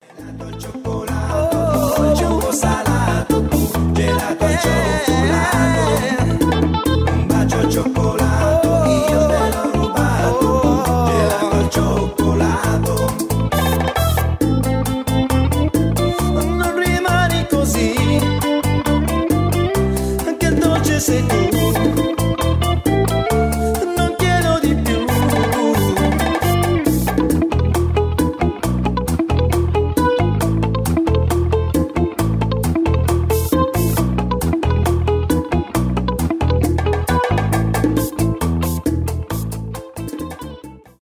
бачата , танцевальные
гитара